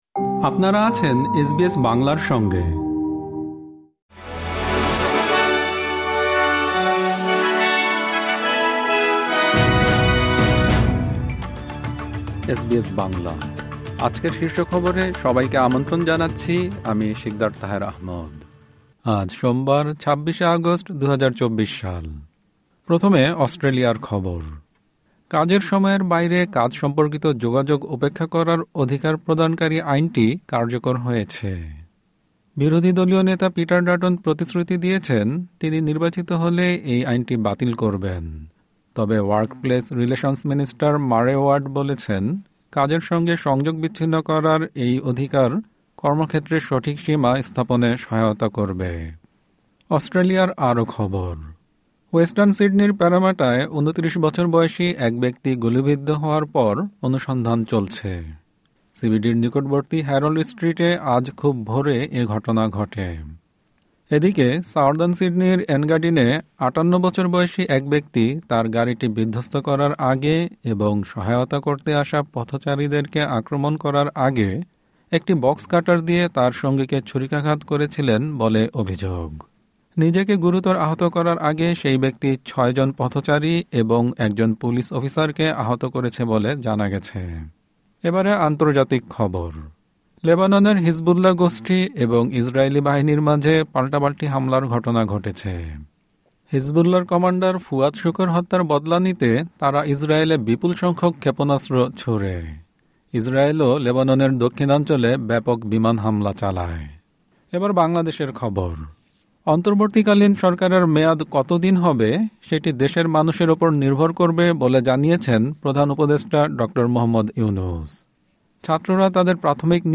এসবিএস বাংলা শীর্ষ খবর: ২৬ আগস্ট, ২০২৪